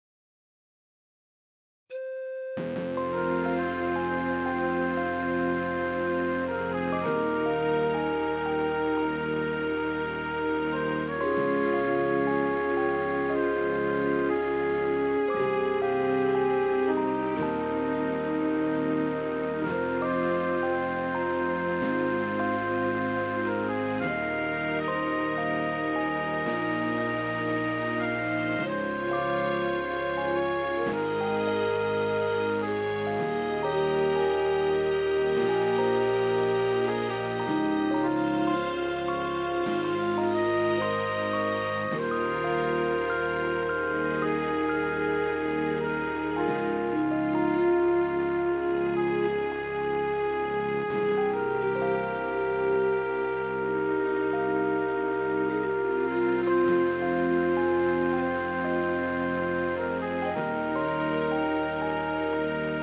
Pan Flute With Flute Melody